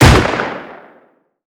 ACE3 / extras / assets / CookoffSounds / shotrocket / close_1.wav
Cookoff - Improve ammo detonation sounds